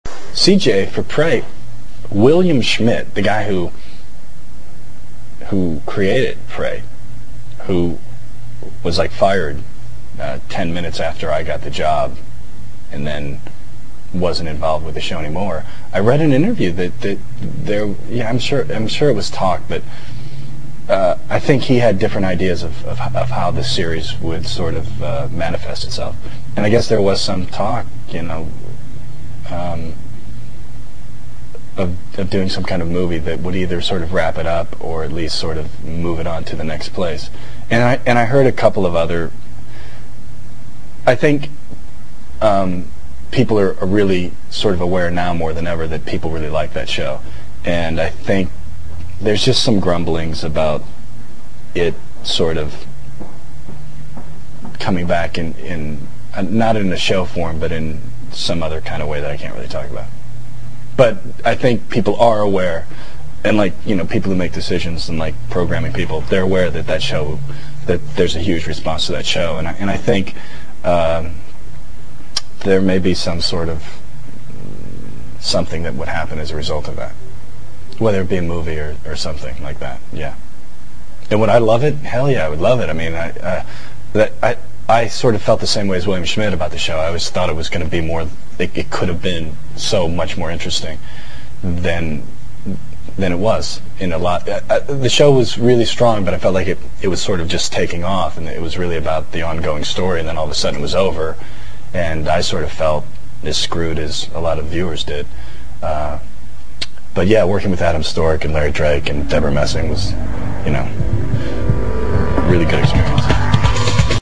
More PREY possible? Vincent answered a question asked by a PREY fan (Dec. 2000)--click